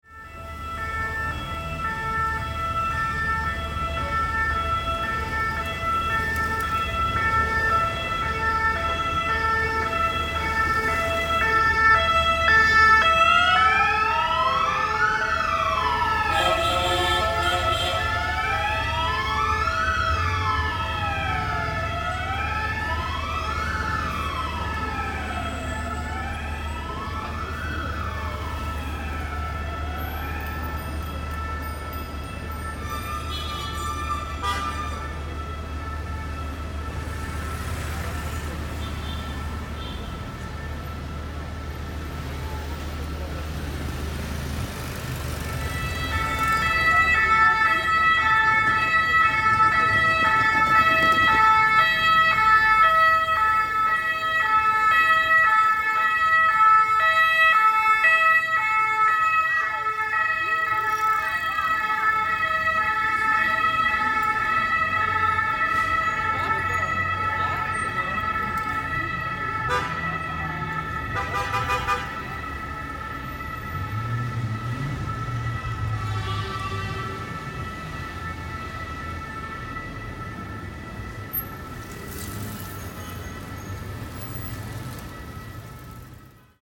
Сборник со звуками кареты скорой помощи для монтажа видео и других проектов.
11. Звук едущей машины скорой помощи в городе с включенной сиреной и обгоняющей машины
sirena-skoroi-v-gorode.mp3